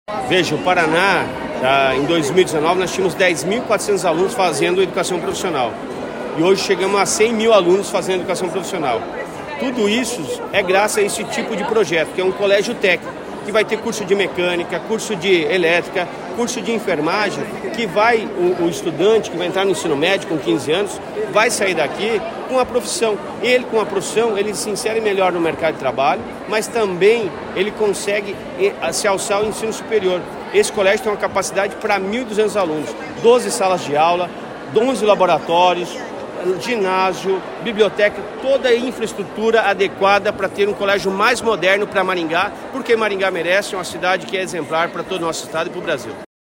Sonora do secretário da Educação, Roni Miranda, sobre a inauguração do Centro de Educação Profissional em Maringá